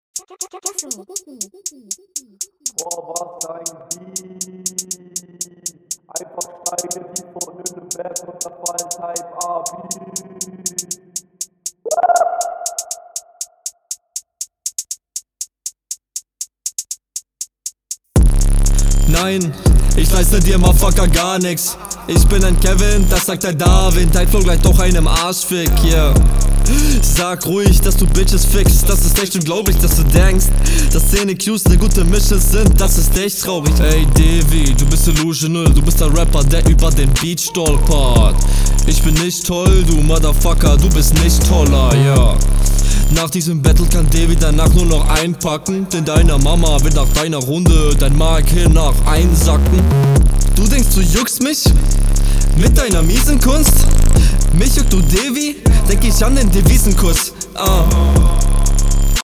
Hey, ich verstehe was du rappst und deinen Flow...